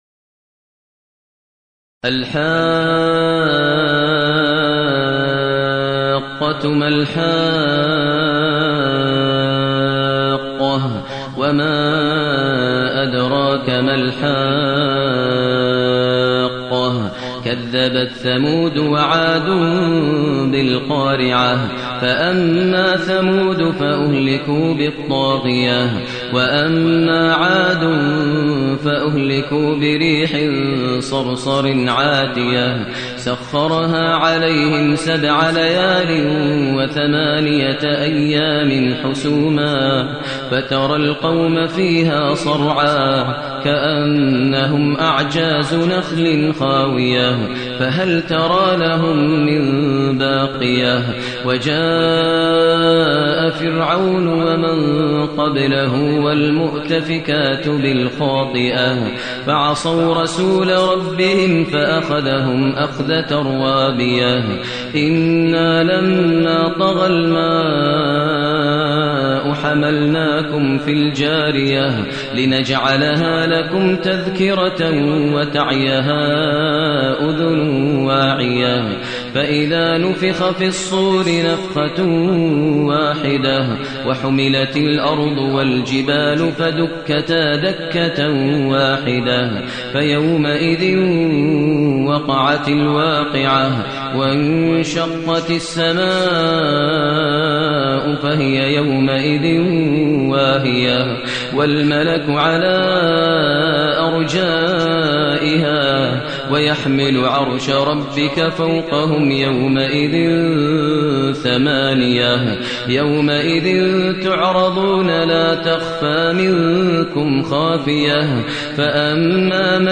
المكان: المسجد الحرام الشيخ: فضيلة الشيخ ماهر المعيقلي فضيلة الشيخ ماهر المعيقلي الحاقة